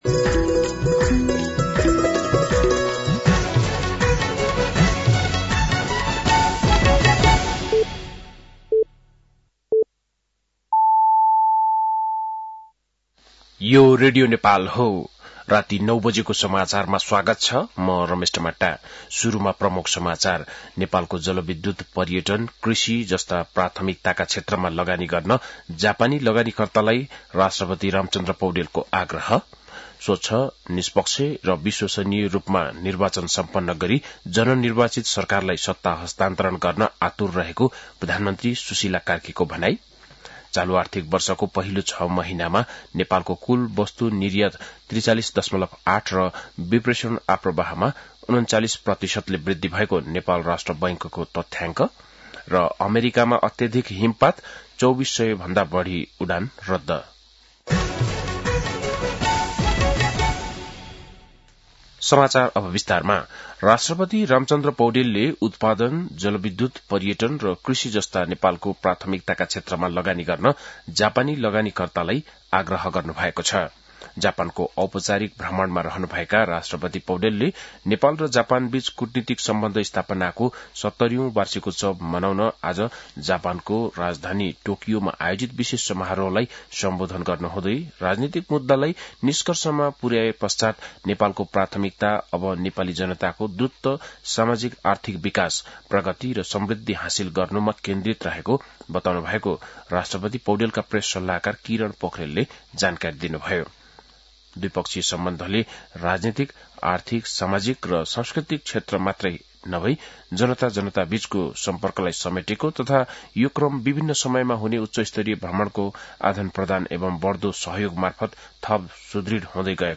बेलुकी ९ बजेको नेपाली समाचार : १९ माघ , २०८२
9-PM-Nepali-NEWS-10-19.mp3